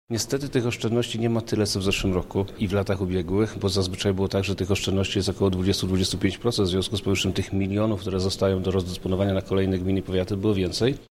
Jak mówi wojewoda lubelski, Przemysław Czarnek, usługi wykonawców podrożały, przez co zmniejszyła się pula dostępnych środków